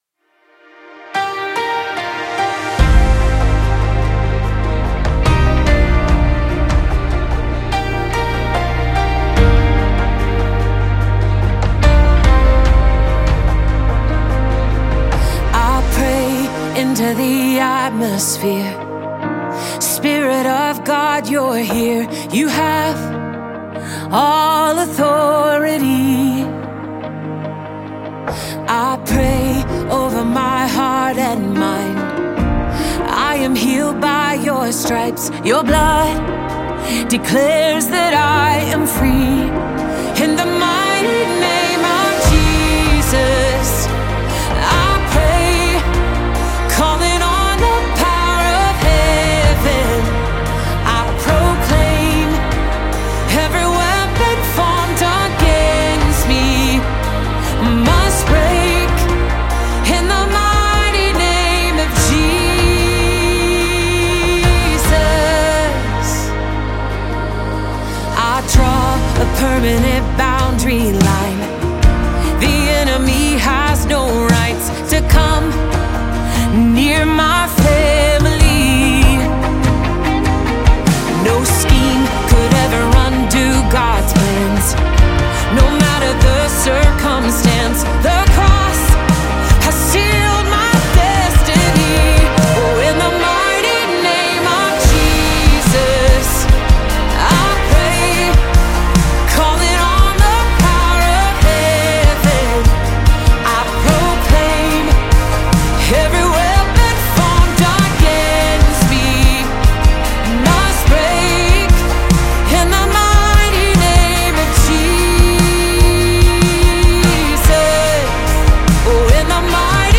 Genres: Christianity, Religion & Spirituality
(Studio Version)